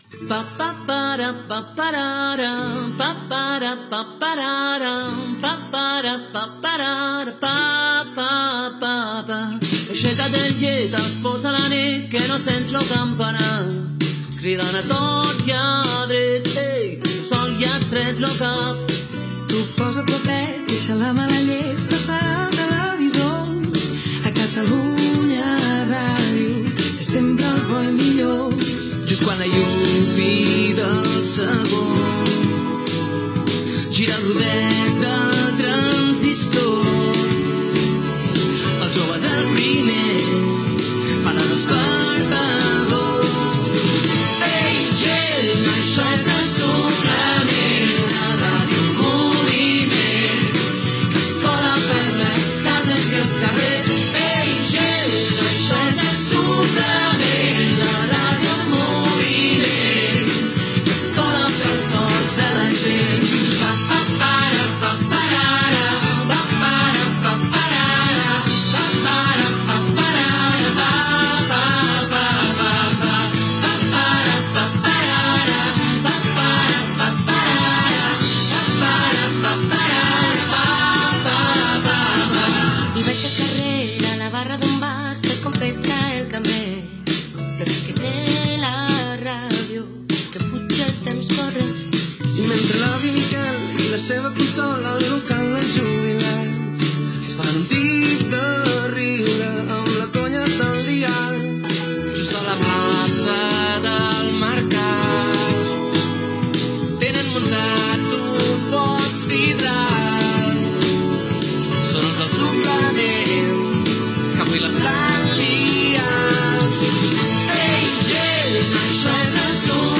Cançó del programa